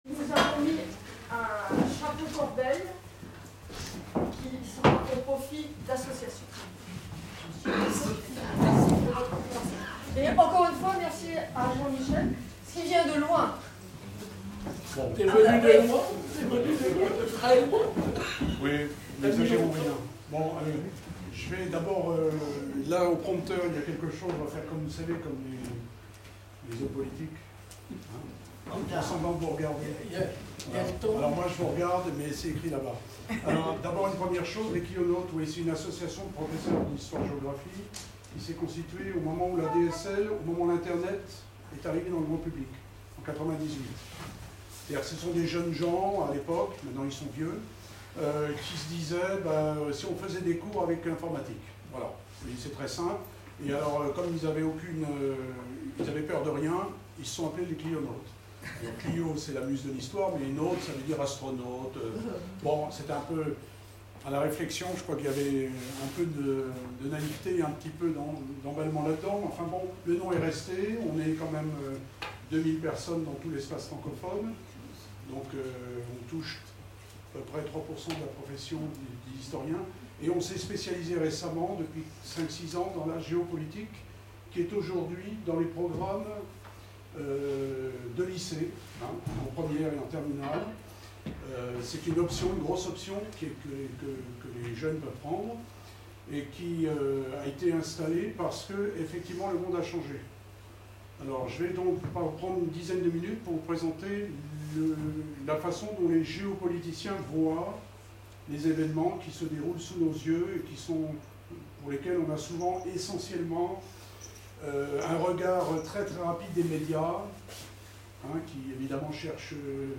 La transcription orale de la conférence